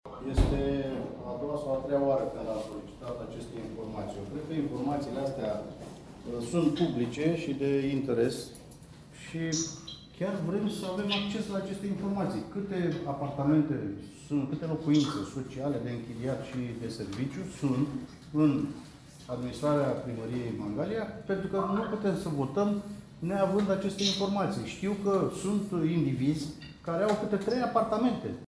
Consilierul AUR Nicolae Moroianu a explicat votul.
Ședința extraordinară s-a desfășurat în sistem mixt.